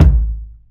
Kick (196).WAV